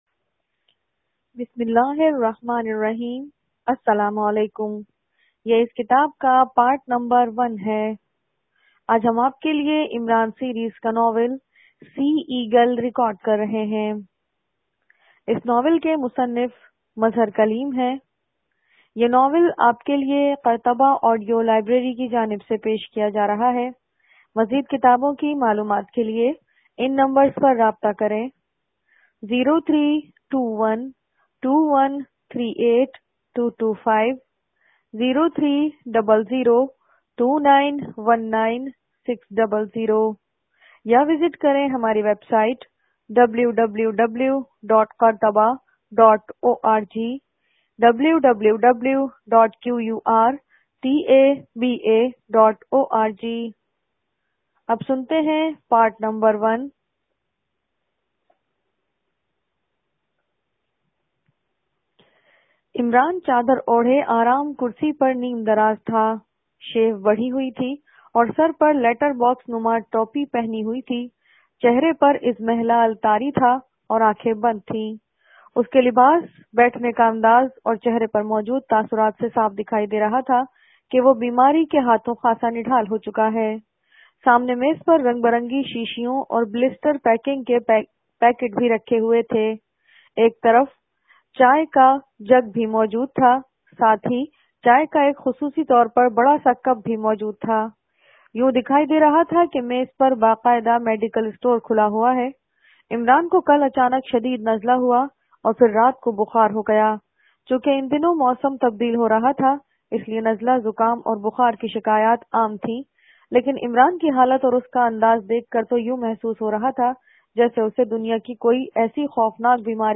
This audio book see eagle part 1 is written by a famous author mazhar kaleem and it is listed under Imran Series category.